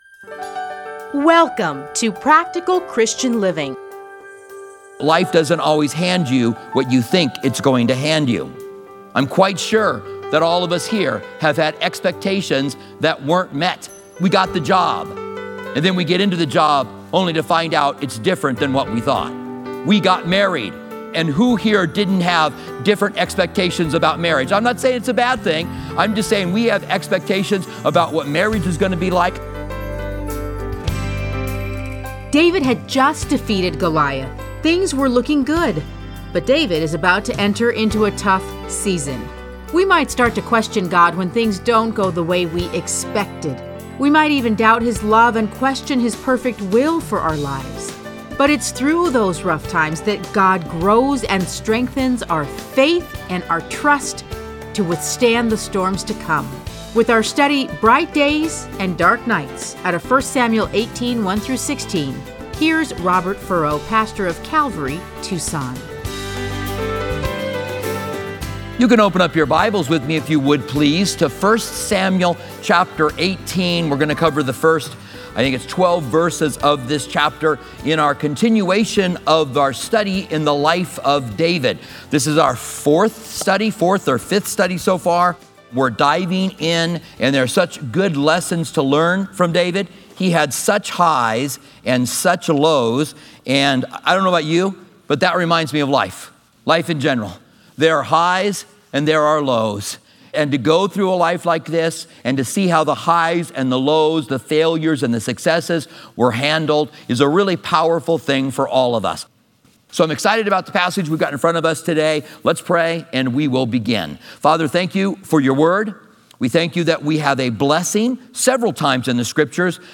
Listen to a teaching from 1 Samuel 18:1-16.